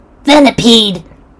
Cries
VENIPEDE.mp3